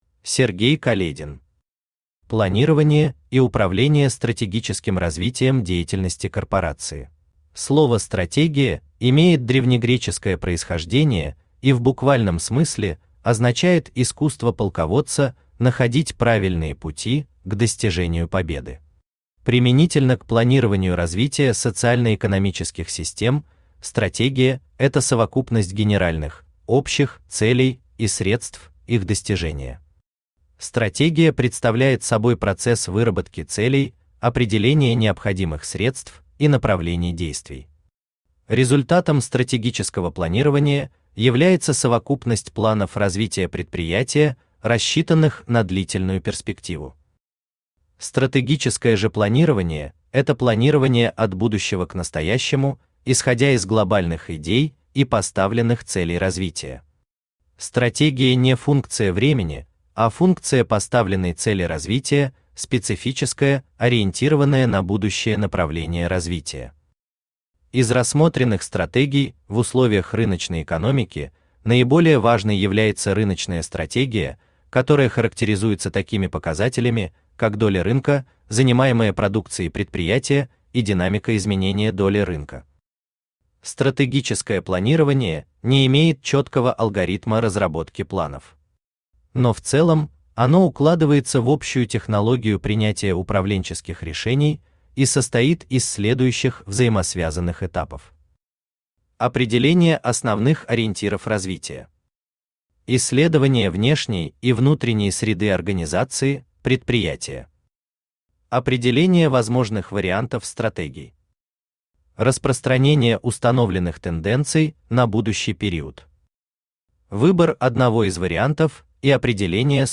Аудиокнига Планирование и управление стратегическим развитием деятельности корпорации | Библиотека аудиокниг
Aудиокнига Планирование и управление стратегическим развитием деятельности корпорации Автор Сергей Каледин Читает аудиокнигу Авточтец ЛитРес.